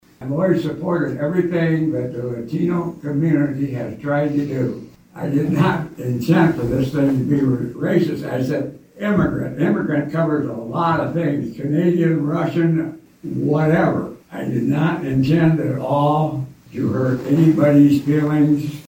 Monday night, during public comment of the Hampton City Council budget workshop meeting, some residents voiced their concerns about councilmember Bill Hodge and a remark he made during the February 3rd meeting about ICE making arrests in the area.